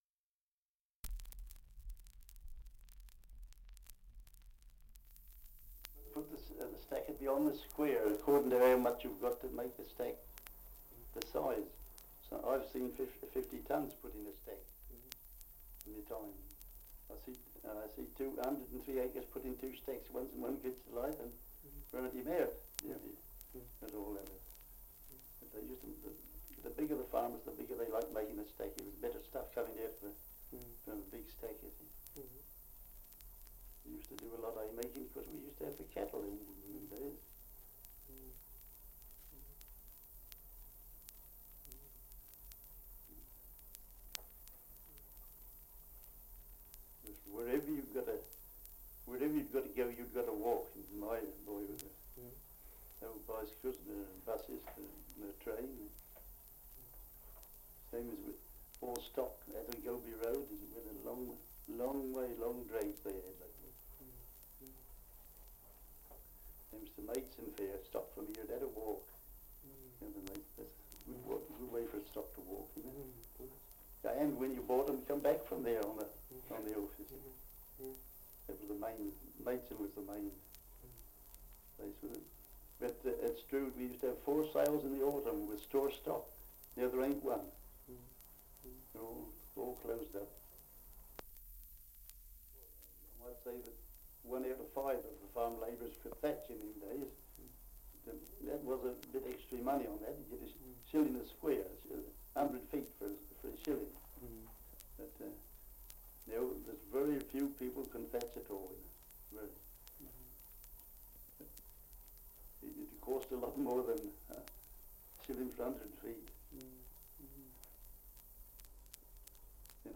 Survey of English Dialects recording in Stoke, Kent
78 r.p.m., cellulose nitrate on aluminium